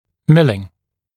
[‘mɪlɪŋ][‘милин]фрезерование, фрезеровка, фрезерный (напр, при изготовлении брекетов или аппаратов)